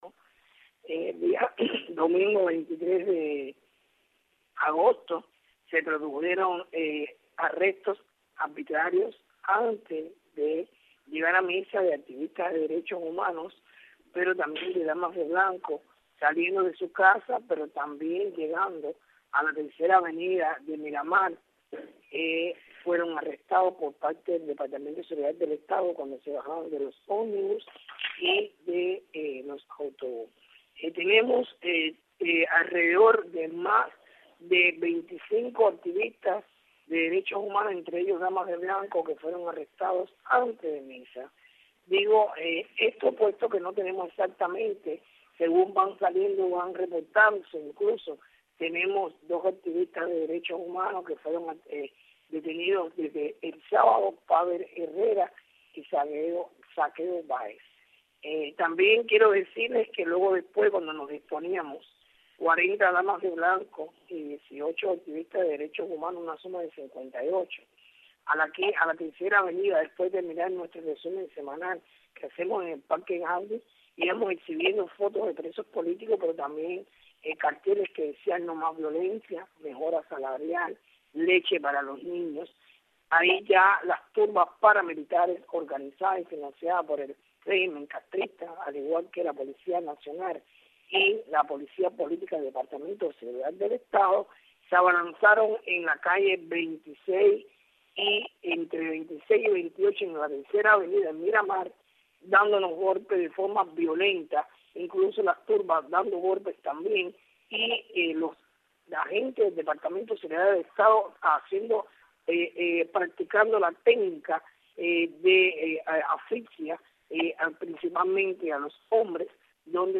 Declaraciones de Berta Soler